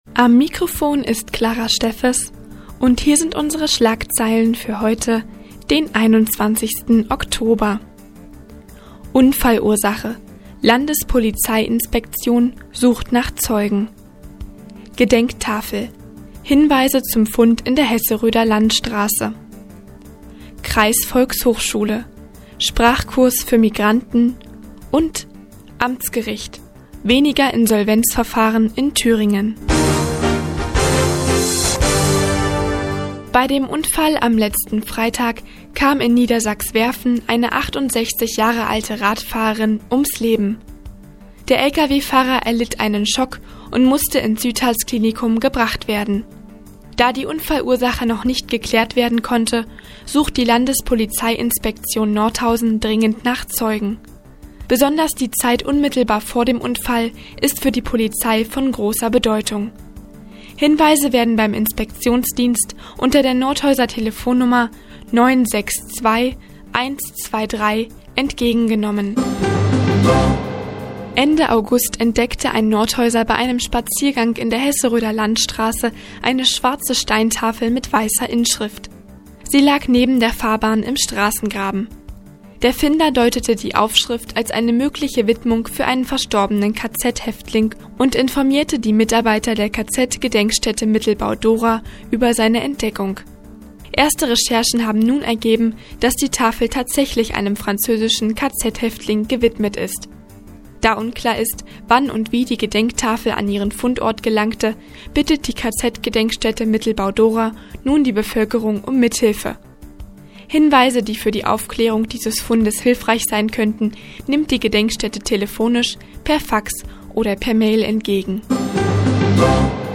Mo, 15:30 Uhr 21.10.2013 „Der Tag auf die Ohren“ okn (Foto: okn) Seit Jahren kooperieren die Nordthüringer Online-Zeitungen und der Offene Kanal Nordhausen. Die tägliche Nachrichtensendung des OKN ist jetzt hier zu hören.